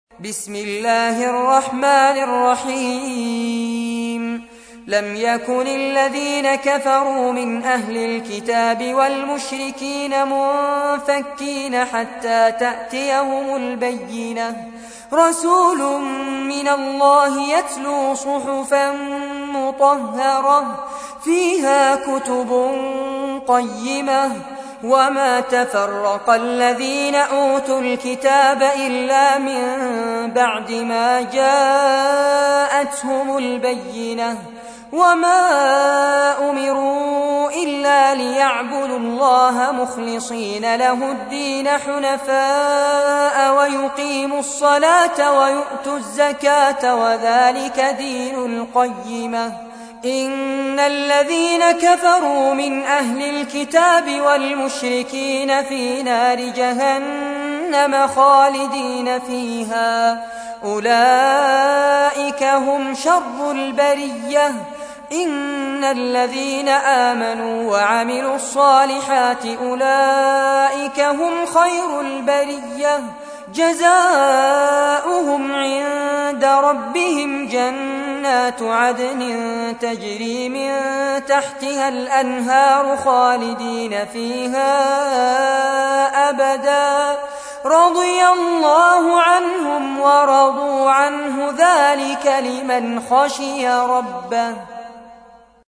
تحميل : 98. سورة البينة / القارئ فارس عباد / القرآن الكريم / موقع يا حسين